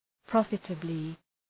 Προφορά
{‘prɒfətəblı} (Επίρρημα) ● επικερδώς